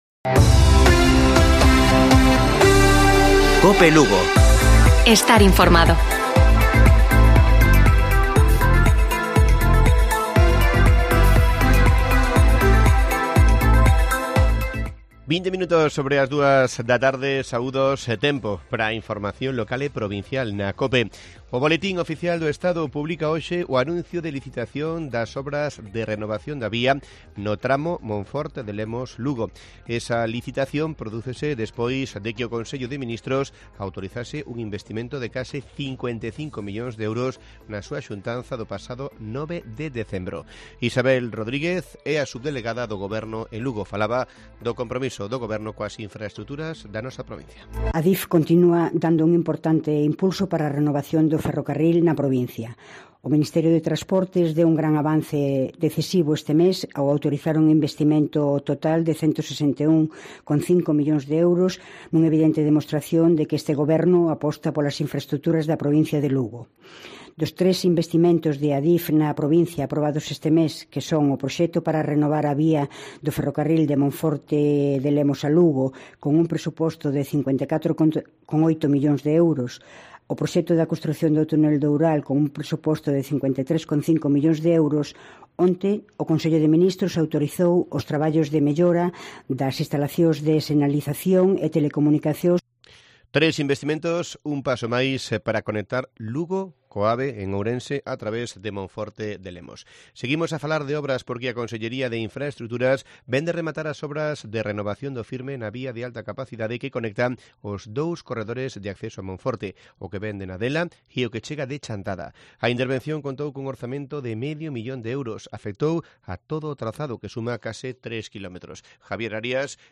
Informativo Mediodía de Cope Lugo. 23 de diciembre. 14:20 horas